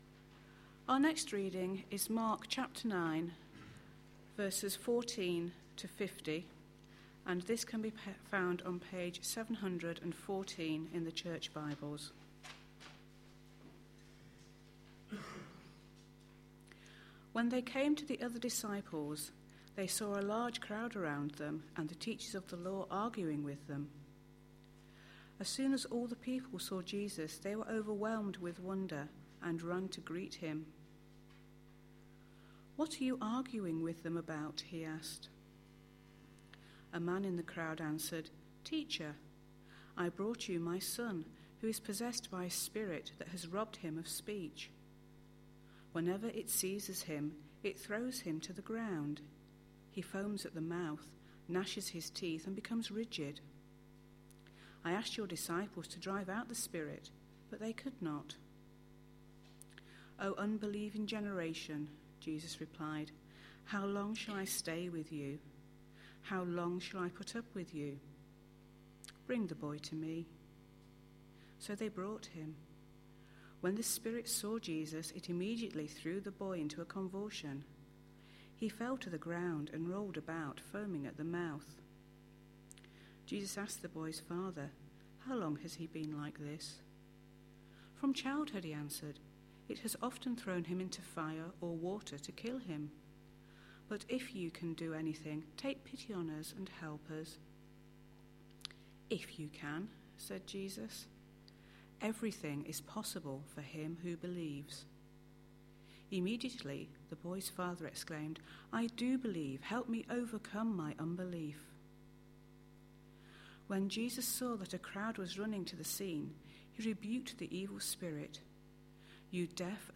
A sermon preached on 12th February, 2012, as part of our Mark series.